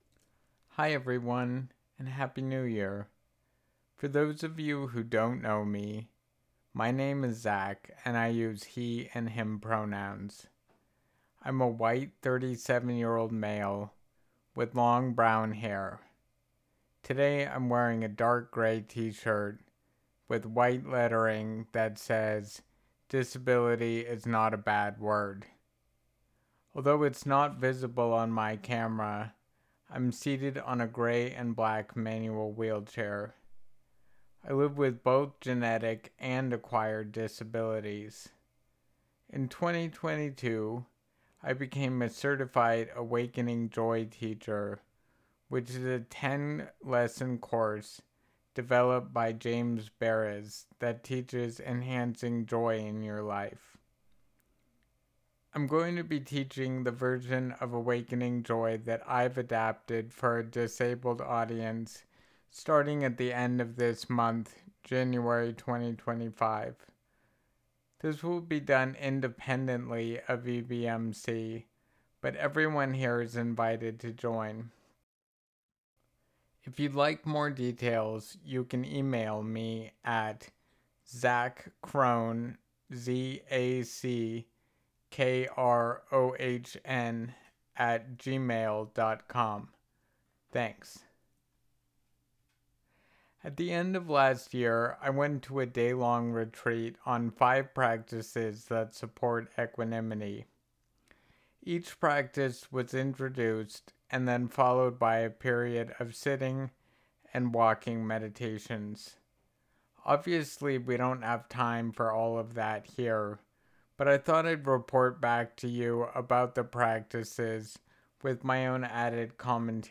Please Note: The following recording was from a script of a talk given at EBEM, but was recorded at another time to preserve the confidentality of participants.